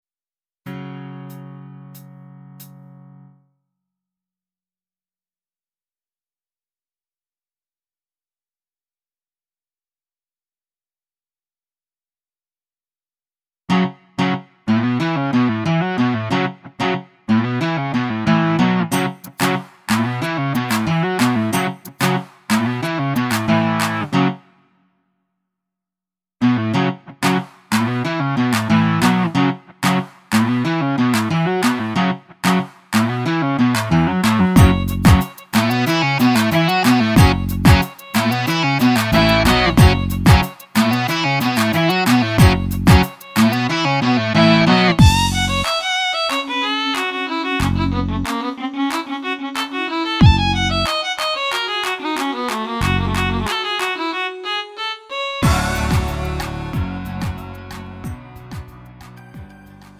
장르 가요 구분 Lite MR